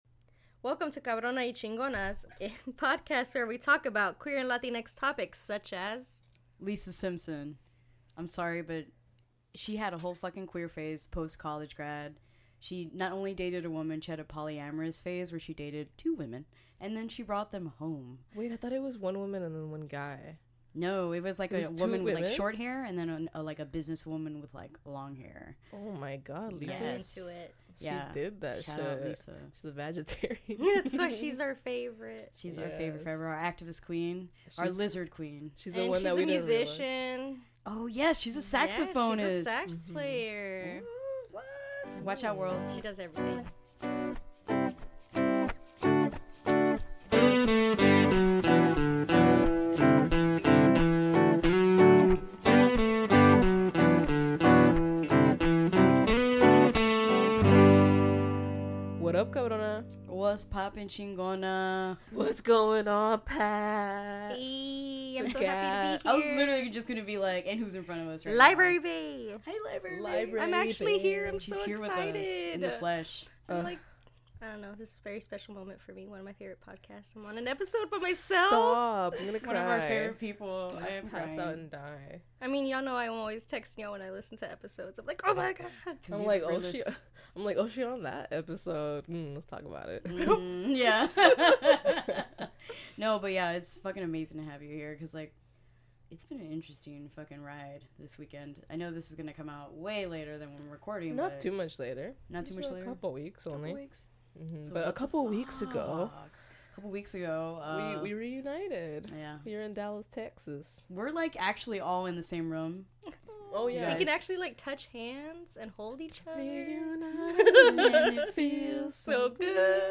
We are joined in Dallas